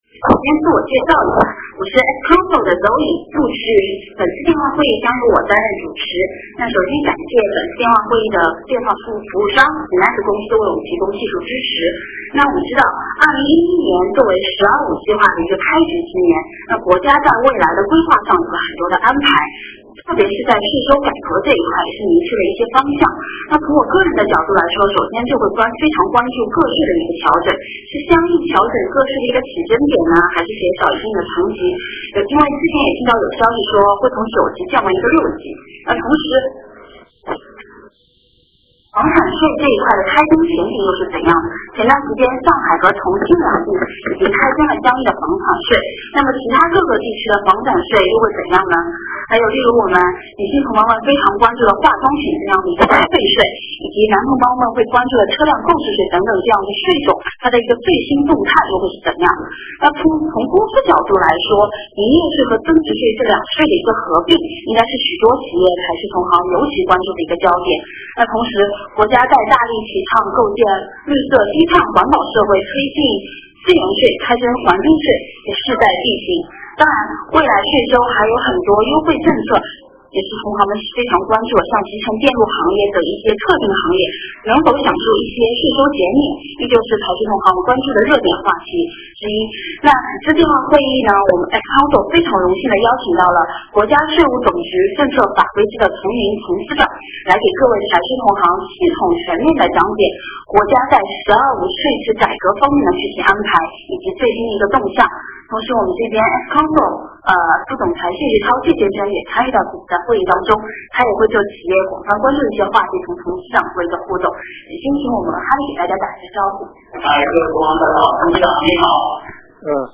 电话会议
互动问答